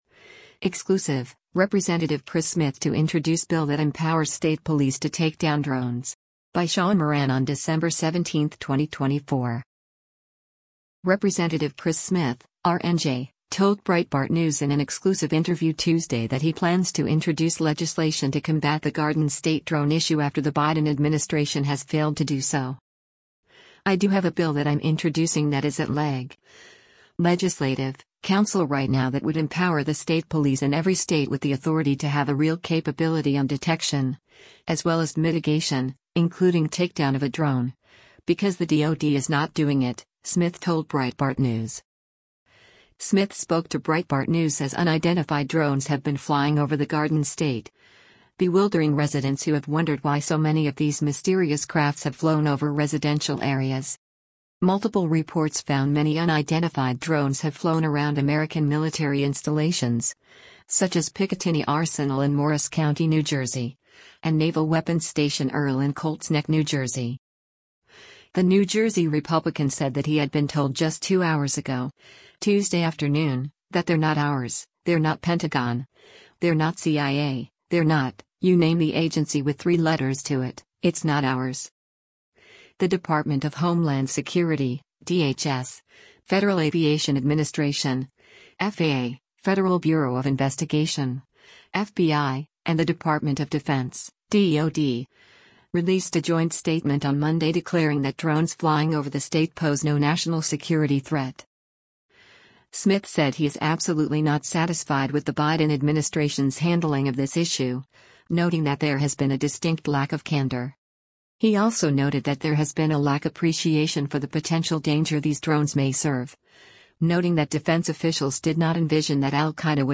Rep. Chris Smith (R-NJ) told Breitbart News in an exclusive interview Tuesday that he plans to introduce legislation to combat the Garden State drone issue after the Biden administration has failed to do so.